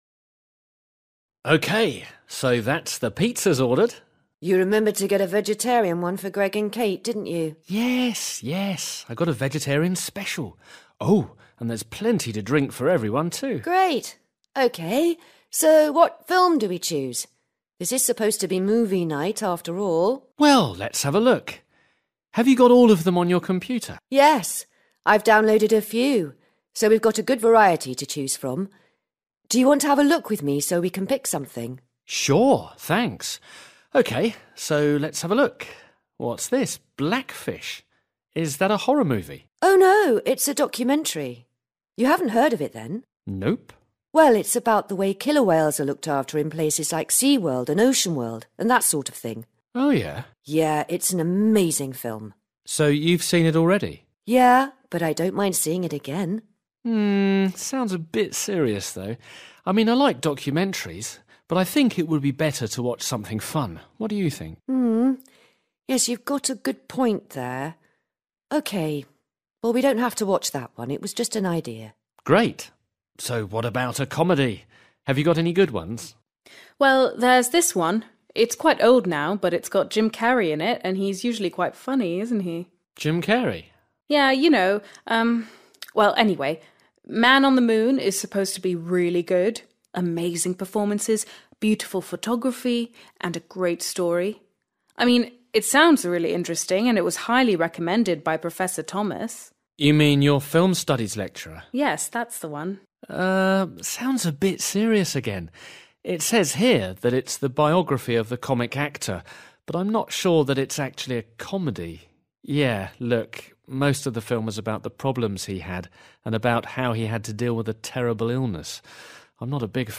A   Listen to three people talking and choose the correct answers.